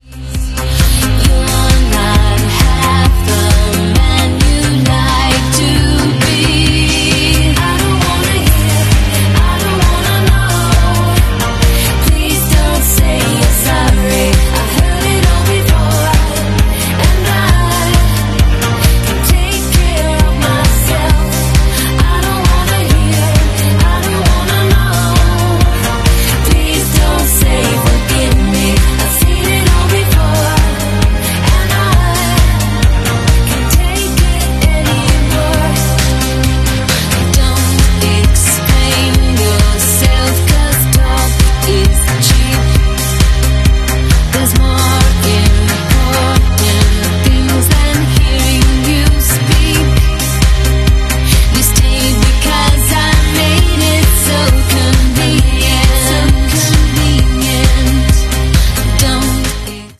Moo! 💖 sound effects free download